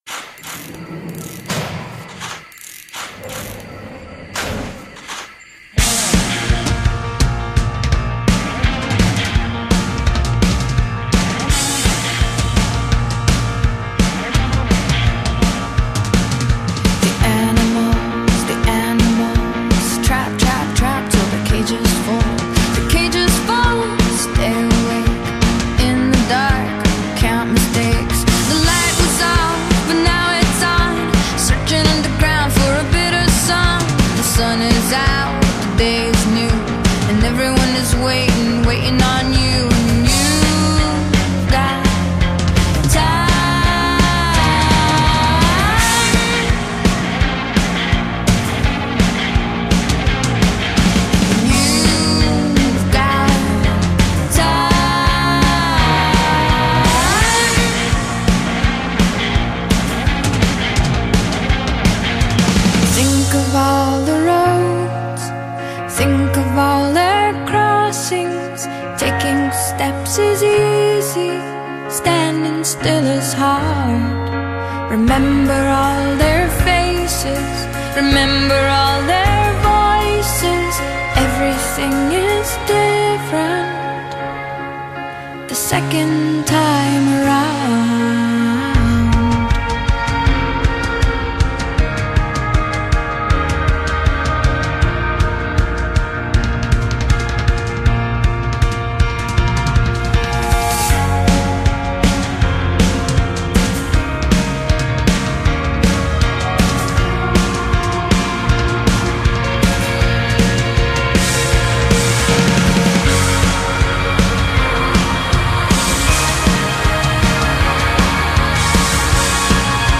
voci delle partigiane